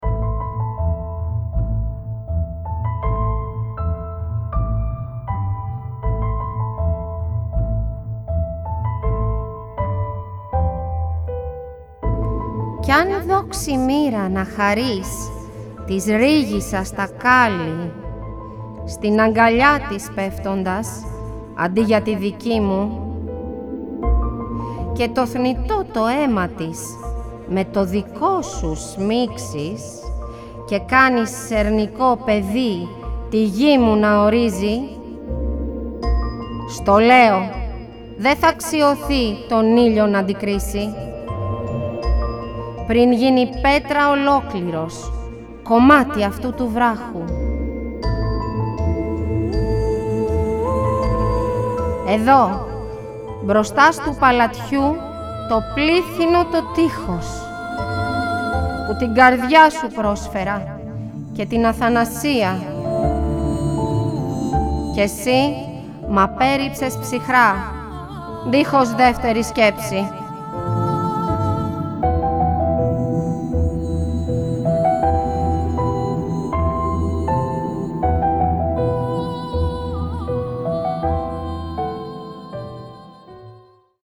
Το μουσικό έργο Μελανοχτυπημένη αποτελείται απο 7 θέματα τα οποία δημιουργήθηκαν ως μουσική υπόκρουση για την απαγγελία των έμμετρων ποιημάτων του ομότιτλου μυθιστορήματος της Αρχοντούλας Αλεξανδροπούλου.
ΑΠΑΓΓΕΛΙΕΣ